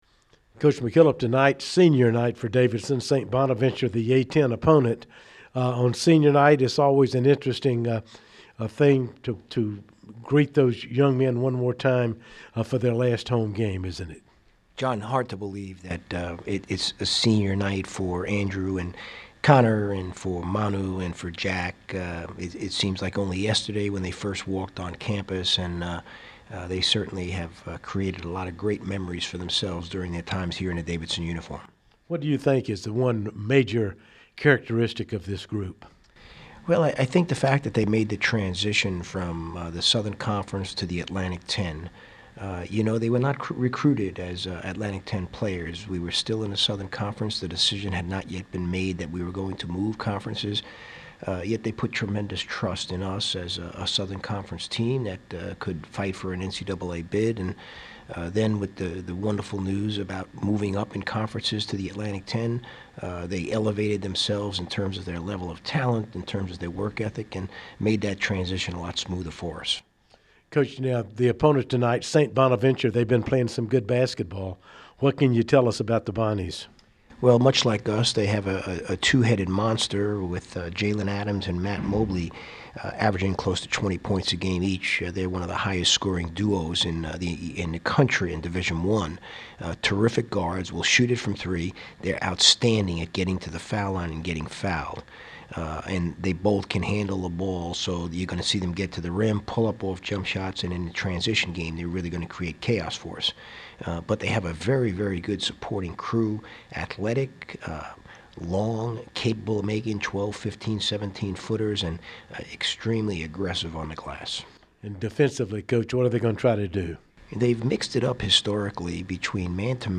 Pregame Radio Interview